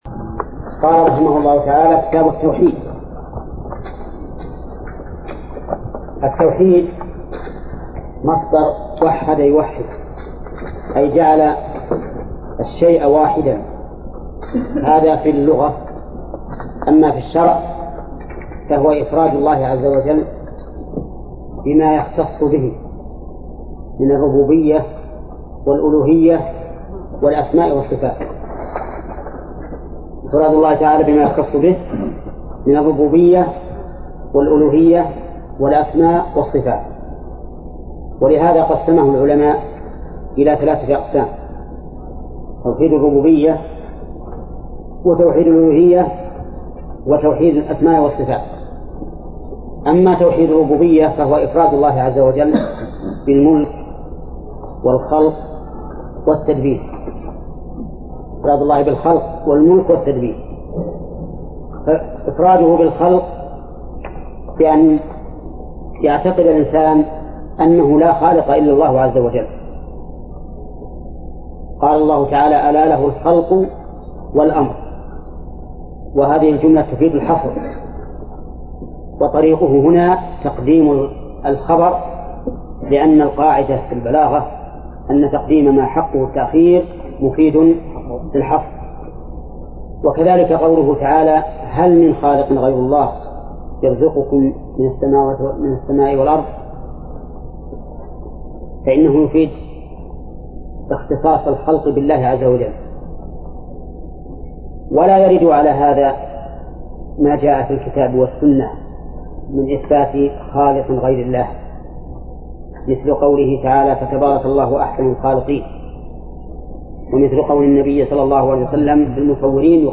الدرس الأول من صفحة (7): بداية الكتاب، إلى صفحة (27): قوله:( الآية الثانية...).